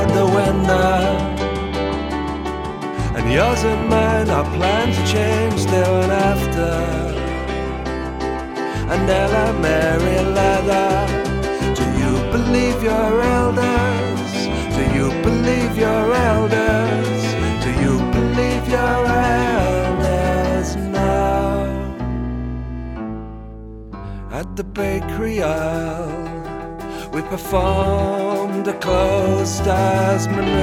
enregistré et mixé en Suède